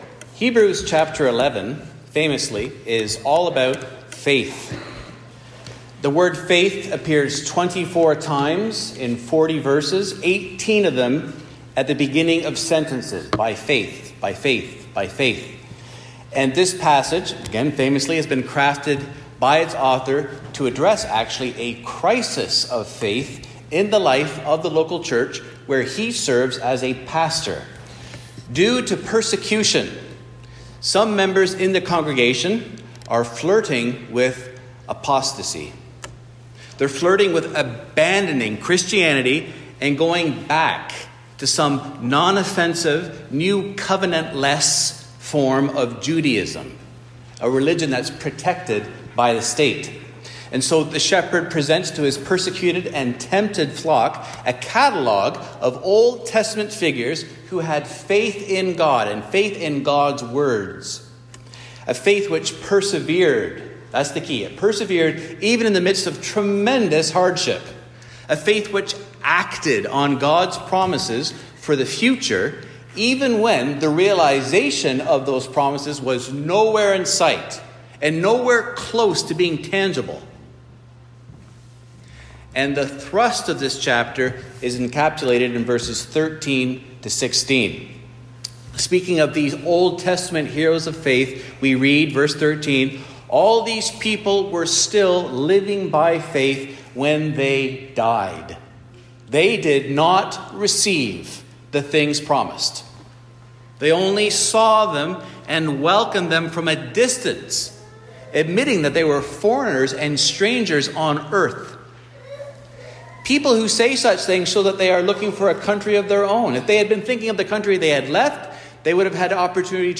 The sermons of Mount Pleasant Baptist Church in Toronto, Ontario.